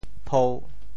潮州发音 潮州 pou1 文 pou3 文